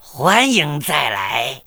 文件 文件历史 文件用途 全域文件用途 Gbn_fw_01.ogg （Ogg Vorbis声音文件，长度1.7秒，117 kbps，文件大小：24 KB） 源地址:游戏语音 文件历史 点击某个日期/时间查看对应时刻的文件。